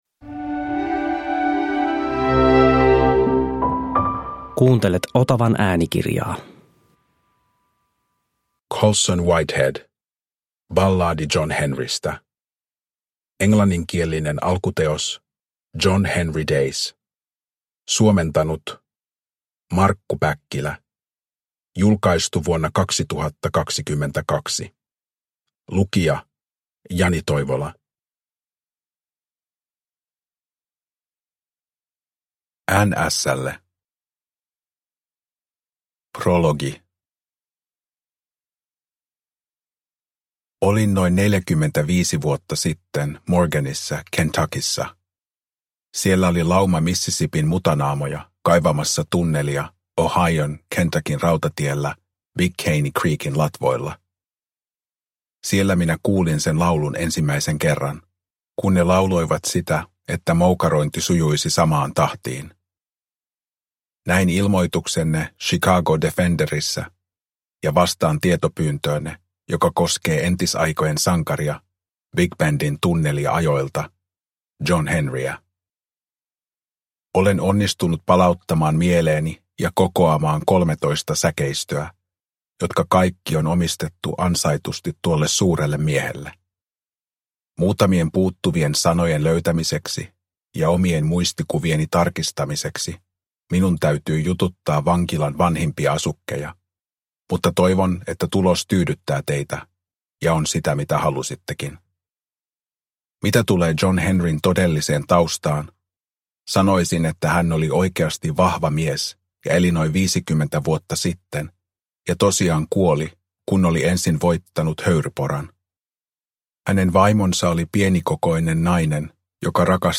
Balladi John Henrystä – Ljudbok – Laddas ner
Uppläsare: Jani Toivola